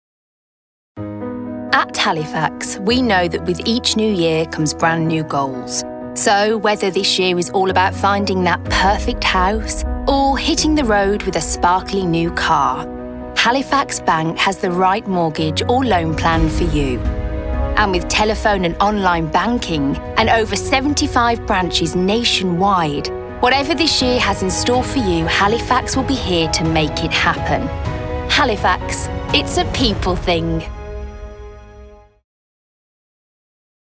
Voice Reel
Halifax - Uplifting, Clear, Trusting
Halifax - Uplifting, Clear, Trusting.mp3